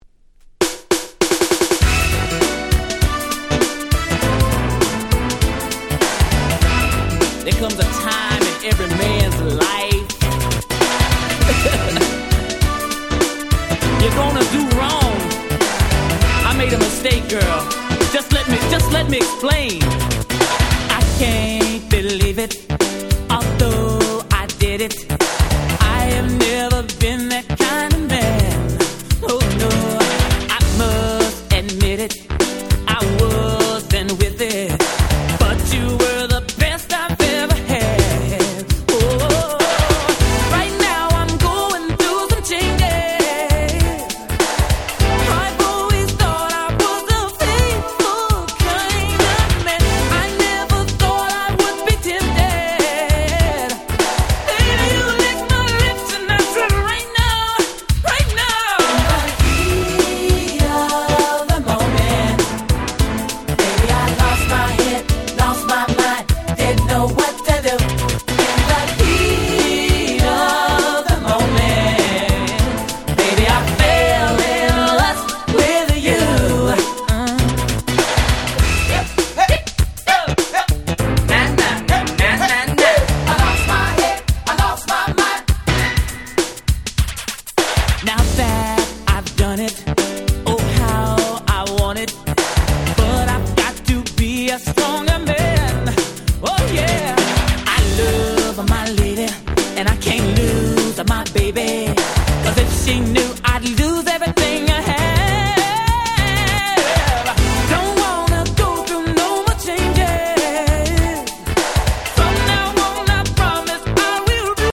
※一部試聴ファイルは別の盤から録音してございます。
90' Very Nice R&B / New Jack Swing !!
New Jack Swing Classics !!